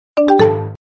เสียงเรียกเข้า MSN
หมวดหมู่: เสียงเรียกเข้า
คำอธิบาย: ด้านบนนี้เป็นเสียงแจ้งเตือนเริ่มต้นของแอปพลิเคชันแชท MSN หากคุณชอบเสียงแจ้งเตือนนี้ คุณสามารถดาวน์โหลดเป็นเสียงเรียกเข้าสำหรับโทรศัพท์ของคุณหรือใช้เพื่อหลอกเพื่อนของคุณว่ามีข้อความ MSN ใหม่เข้ามา 😜